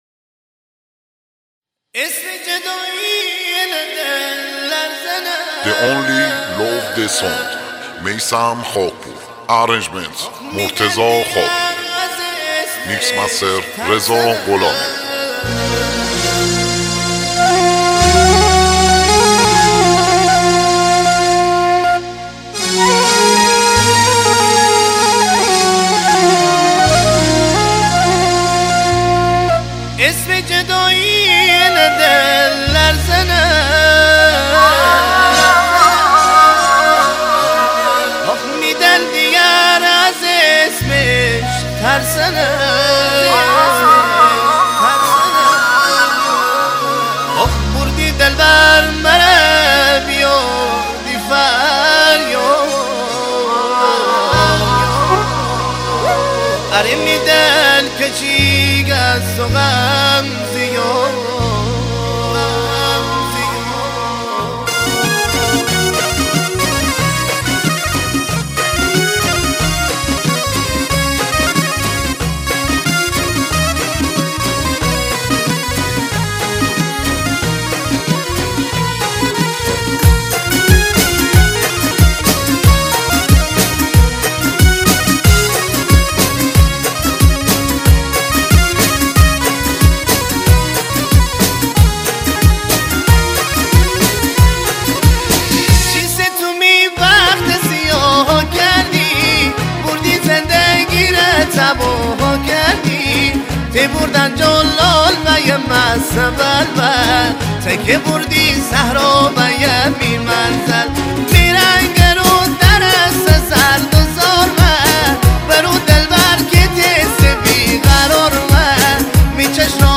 آهنگ مازندرانی
آهنگ غمگین